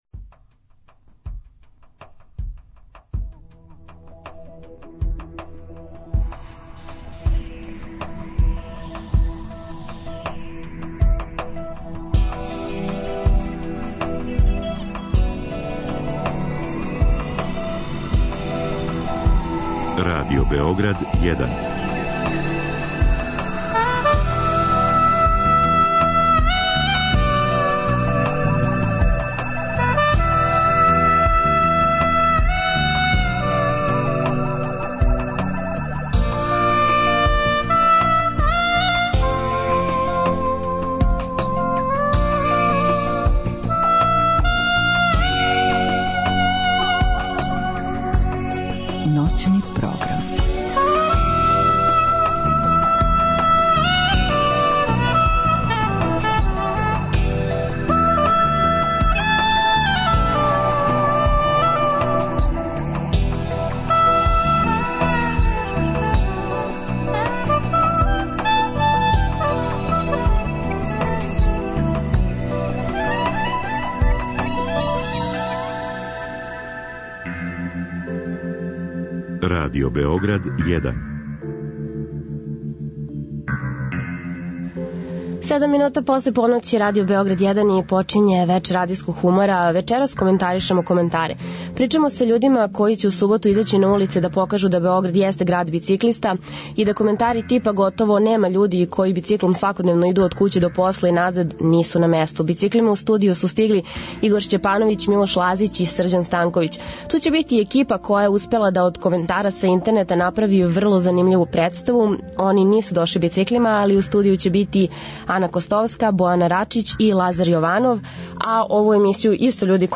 Вечерас од 00:05 на таласима Радио Београда 1 коментаришемо коментаре.